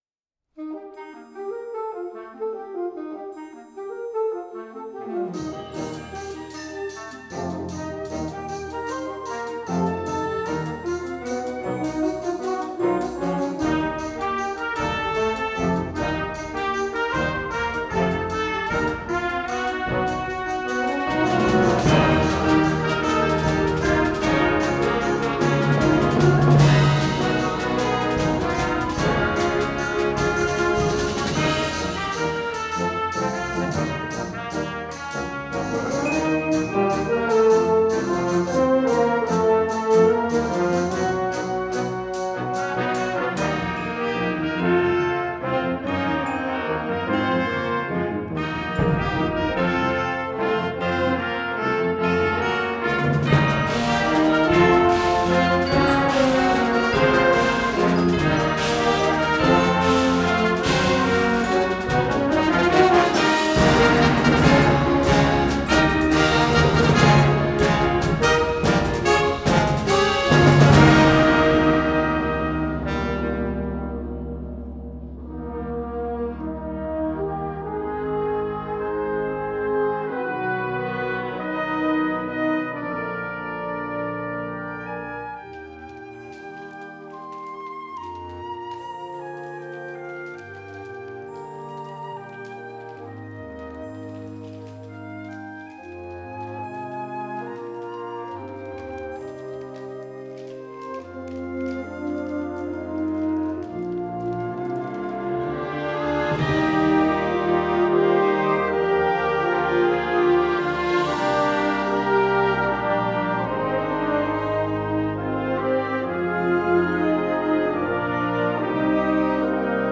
Full of fast-paced rhythmic interest
Concert Band